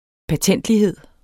Udtale [ pæɐ̯ˈtεnˀdliˌheðˀ ]